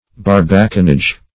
Search Result for " barbacanage" : The Collaborative International Dictionary of English v.0.48: Barbacanage \Bar"ba*can*age\, n. See Barbicanage .